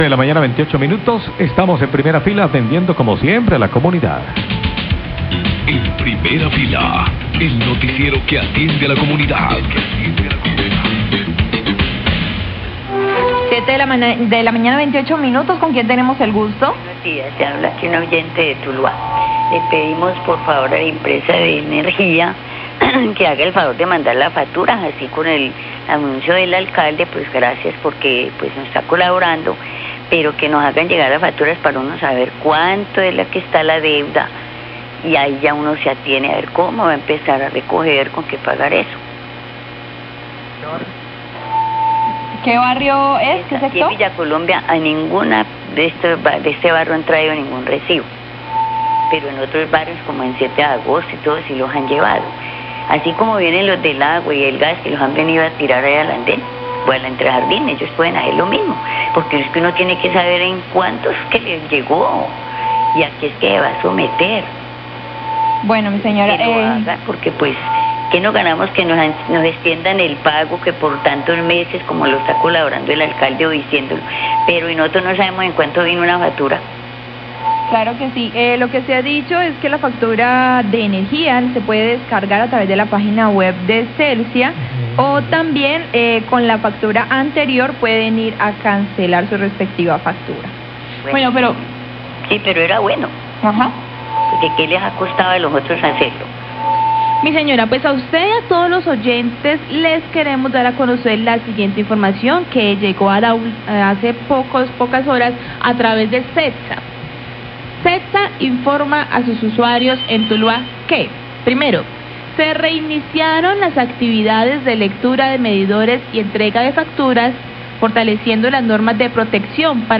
Radio
Oyente pide que los recibos de energía lleguen a la casa así como llegan los demás, periodista le informan que Cetsa ya reinició con la entrega de recibos y la lectura de los medidores.